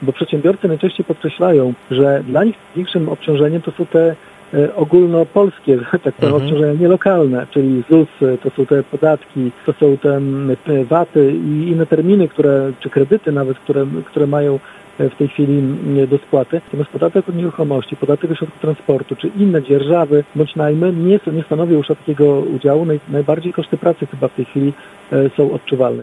Jeżeli chodzi o pomoc rządową dla biznesu, to burmistrz Giżycka oczekuje bardziej zdecydowanych działań.